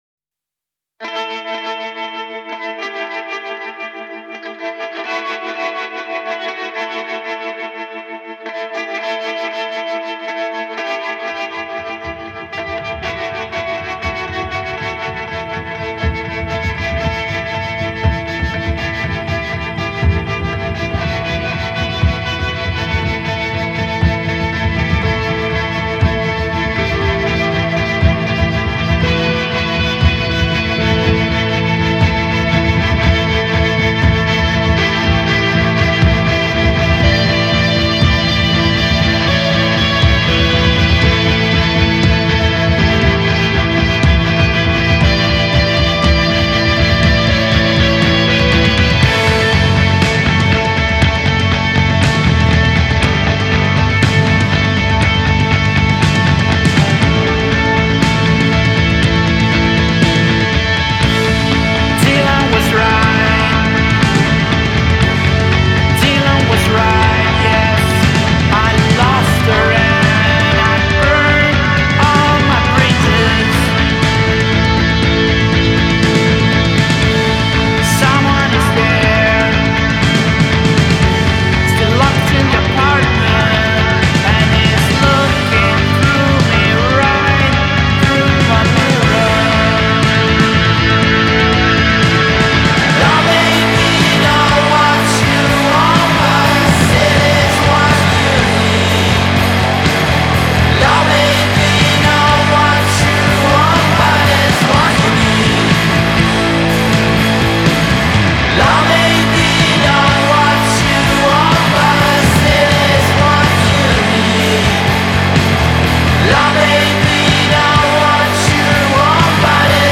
genre: Alternative Rock / Shoegaze